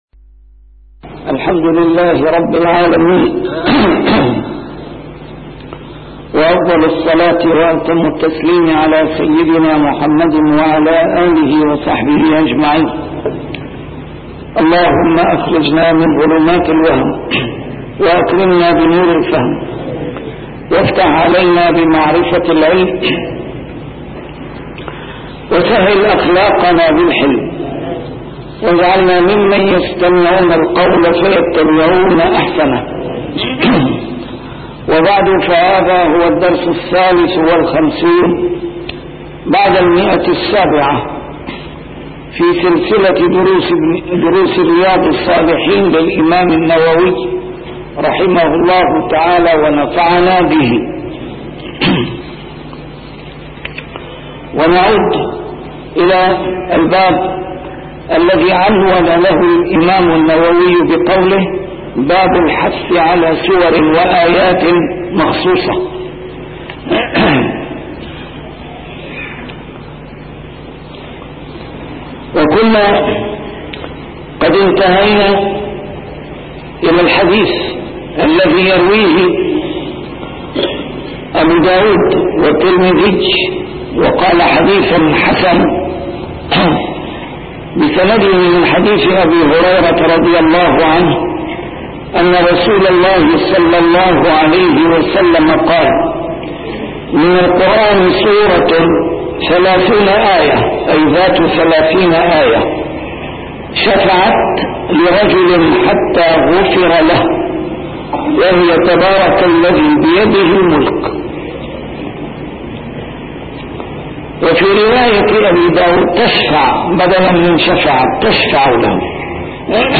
شرح كتاب رياض الصالحين - A MARTYR SCHOLAR: IMAM MUHAMMAD SAEED RAMADAN AL-BOUTI - الدروس العلمية - علوم الحديث الشريف - 753- شرح رياض الصالحين: الحث على سور وآيات مخصوصة